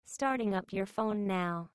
Alert Tone